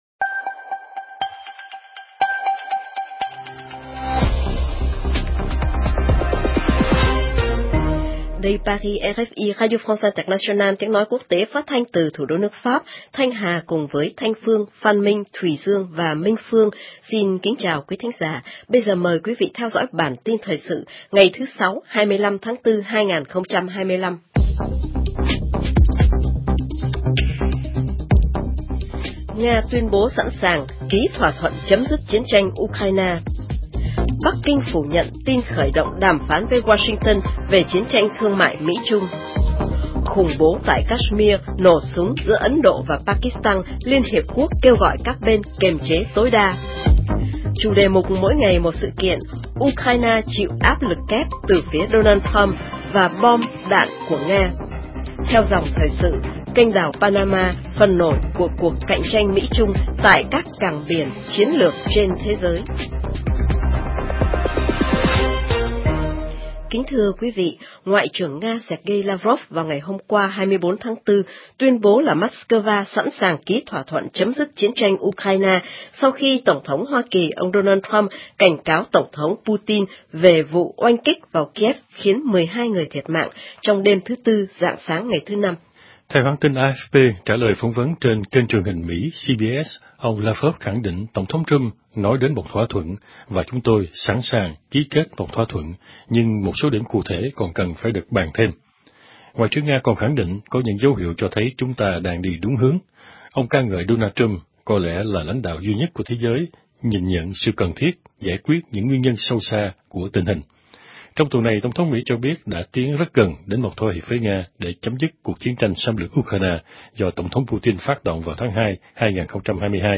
>Chương trình phát thanh hàng ngày của RFI Tiếng Việt được truyền trực tiếp và lưu giữ trên YOUTUBE.